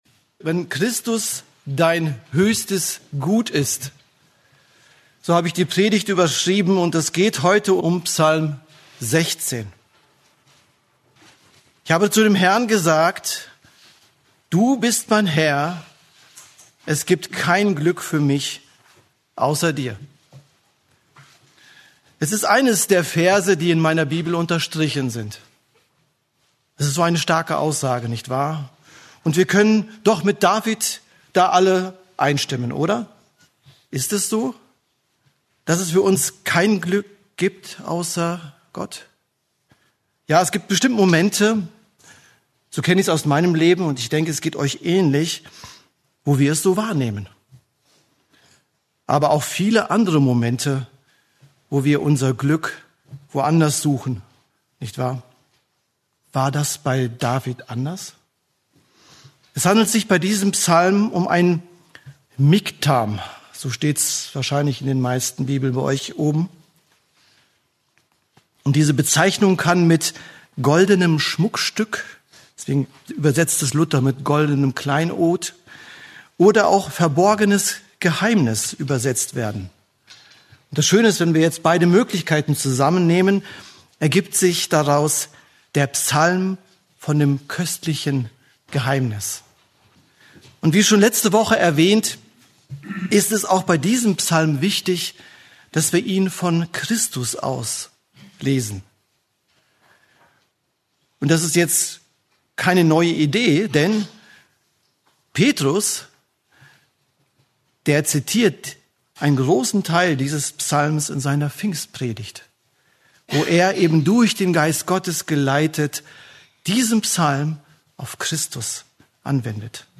Eine predigt aus der serie "Einzelpredigten."